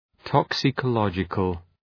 Προφορά
{,tɒksıkə’lɒdʒıkəl}